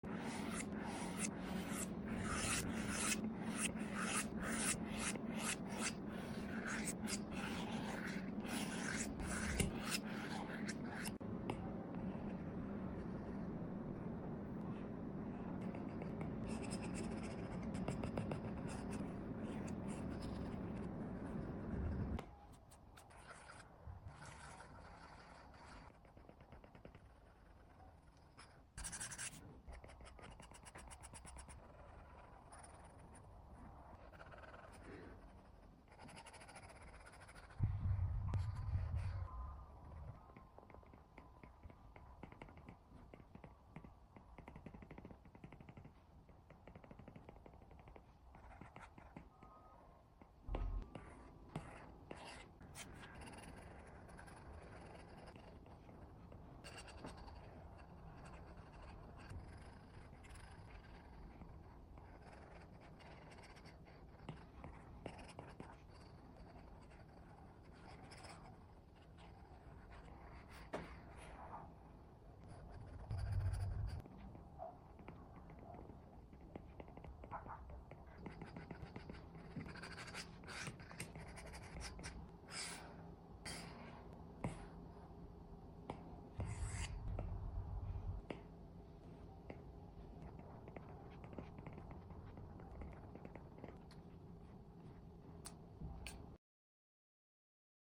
ASMR..This video focuses on how